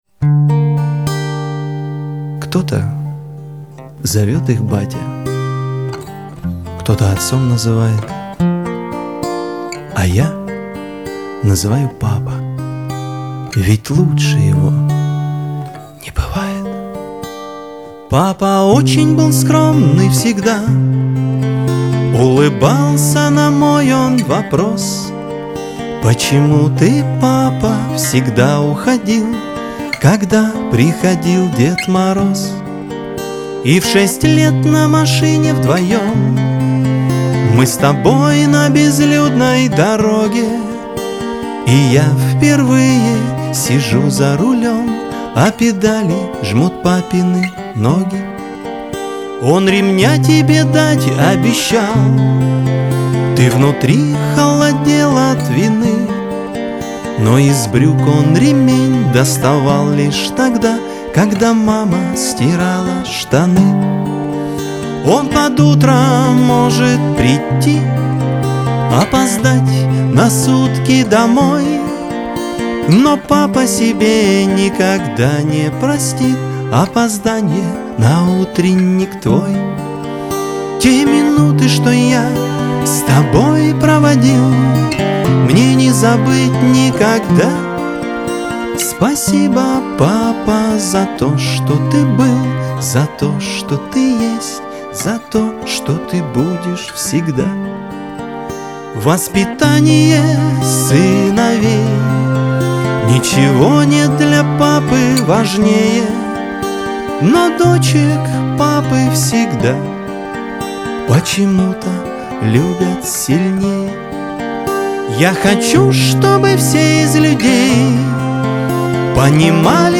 эстрада , pop
грусть